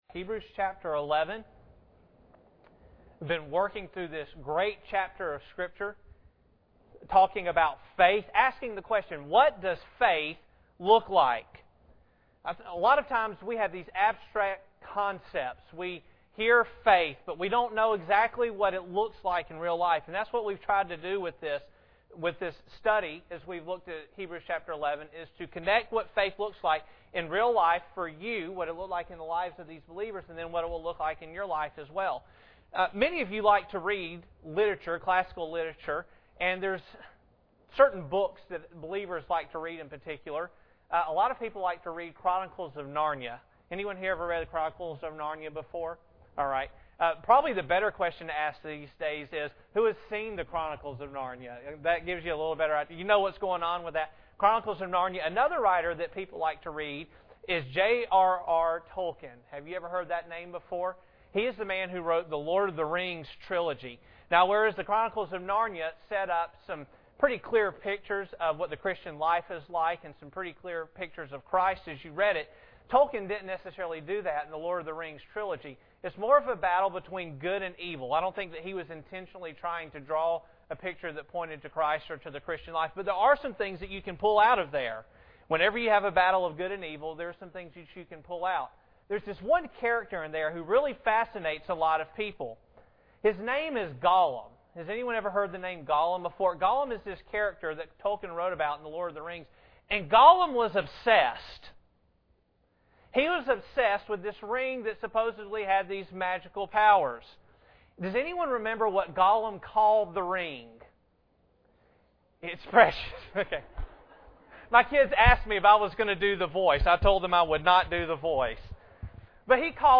Hebrews 11:17-19 Service Type: Sunday Morning Bible Text